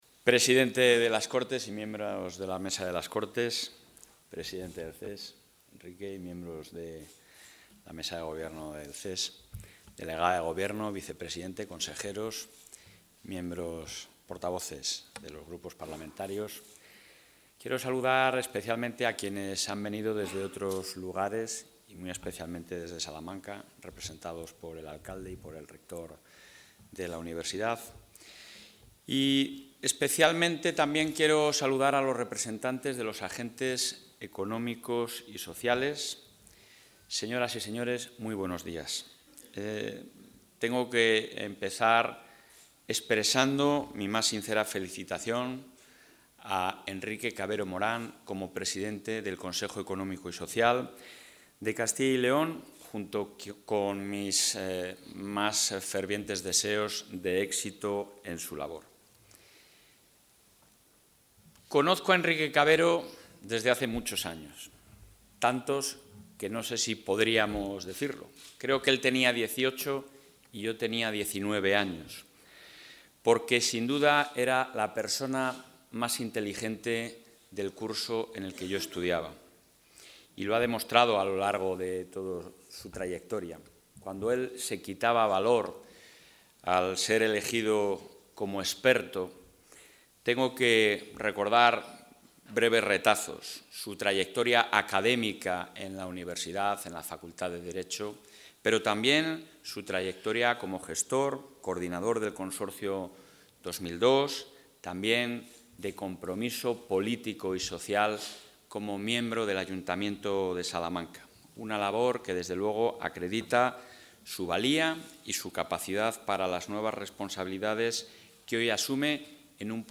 Intervención del presidente.